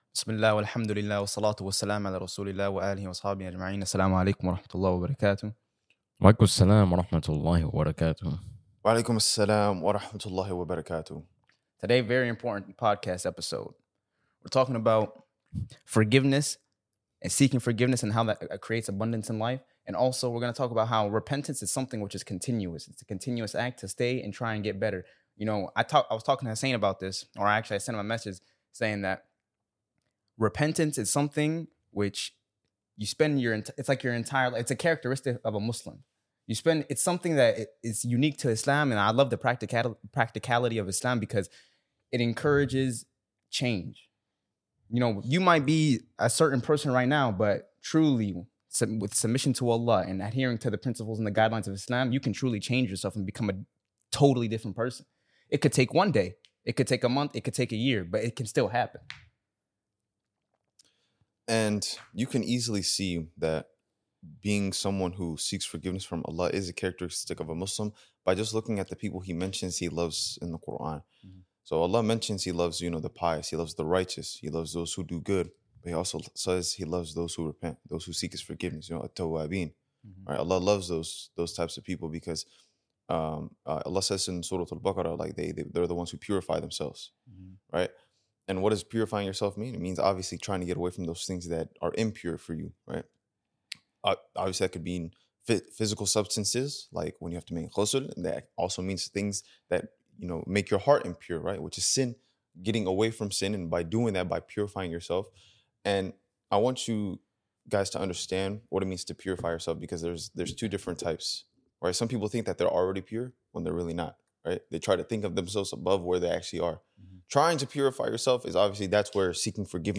DeenTour is a podcast and channel where 3 brothers showcase their love for islam through reminders, brotherhood, motivation, entertainment, and more!